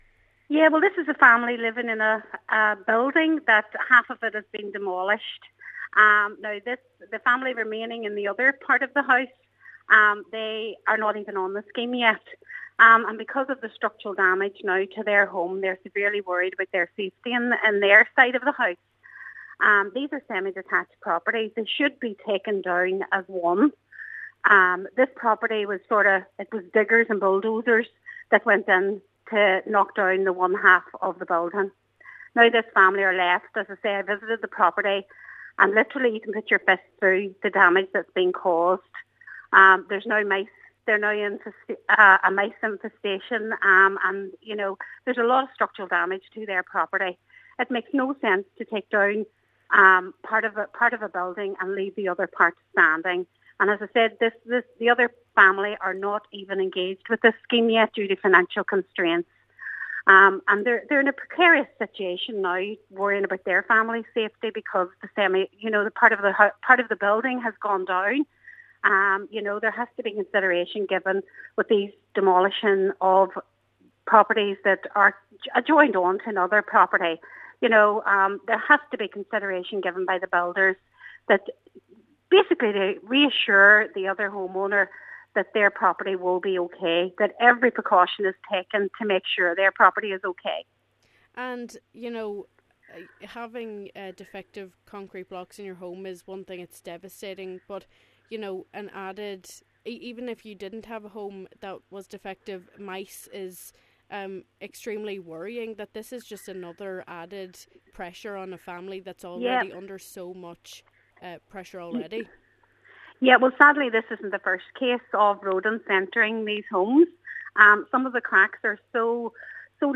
Cllr Beard says the system at present does not work and people’s health are at risk because of that: